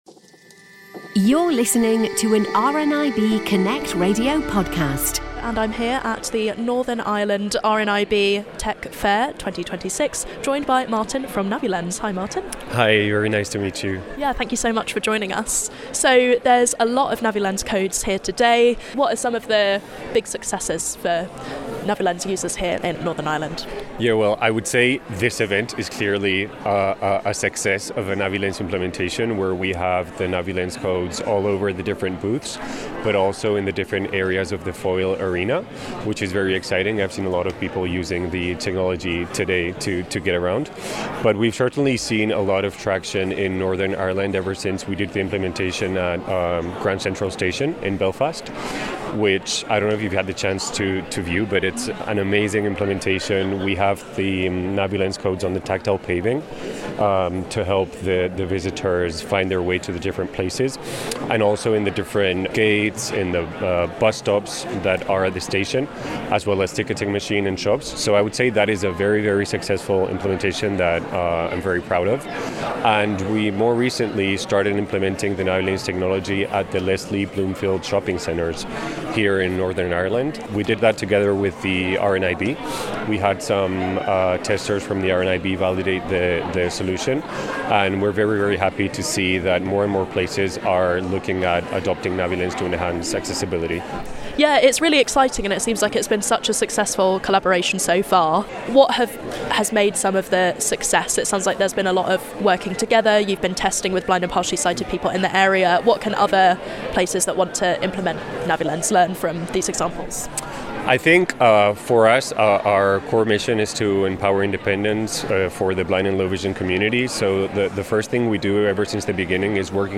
RNIB Northern Ireland Technology Fair 2026 took place on Wednesday 11th of February at the Foyle Arena in Derry/Londonderry.